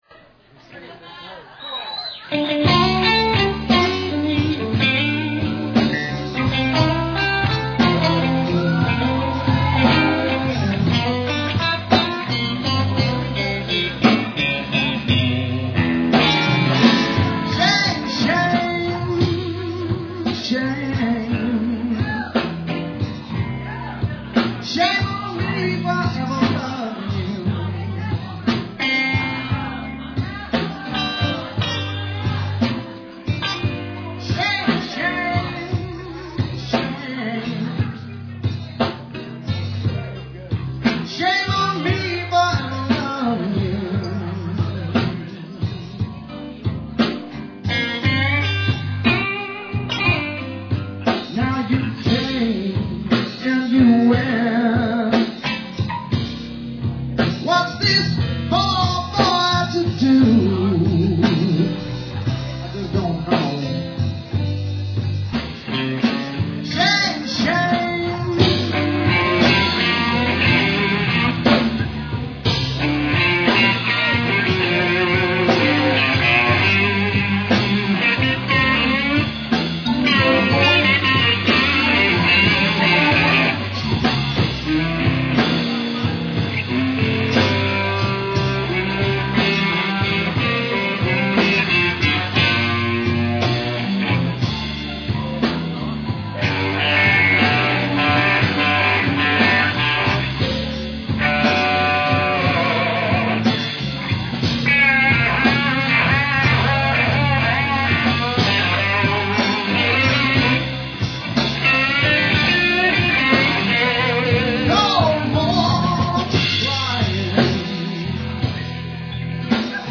singing and playing the blues...live at Blind Melon's!